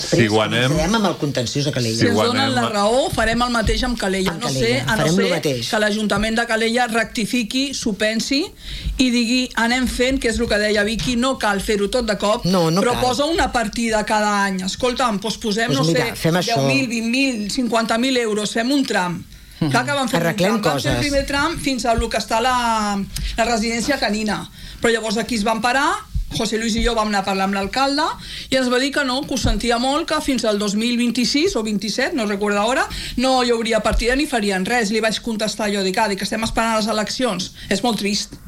L’associació veïnal ha anunciat en una entrevista a RCT que, en les pròximes setmanes, presentarà un recurs contenciós administratiu per tal que el consistori reconegui la recepció tàcita de la urbanització i assumeixi el manteniment dels serveis bàsics, com ara l’asfaltatge, la neteja viària i l’enllumenat públic.